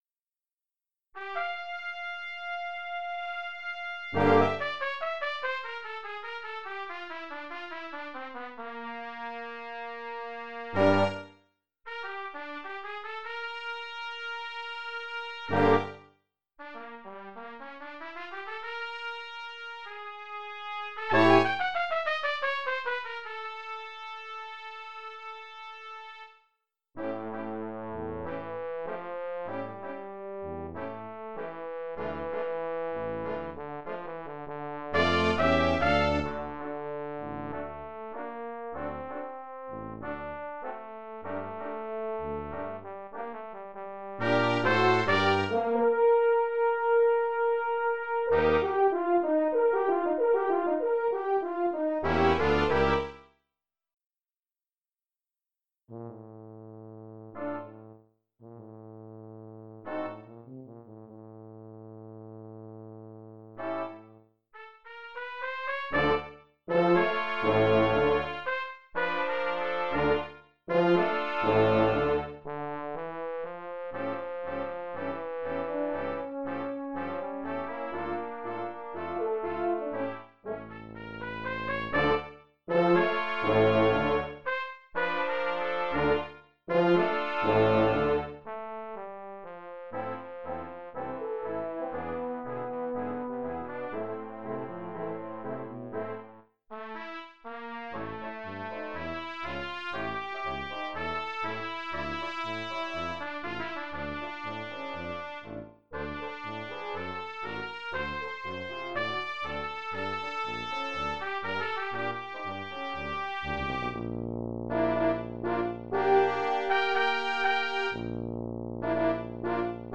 (2 Trp, Hn, Trb, Bastuba) (5 min.).
tango tzigane